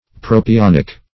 propionic - definition of propionic - synonyms, pronunciation, spelling from Free Dictionary
Propionic \Pro`pi*on"ic\, a. [Proto- + Gr. pi`wn fat.] (Chem.)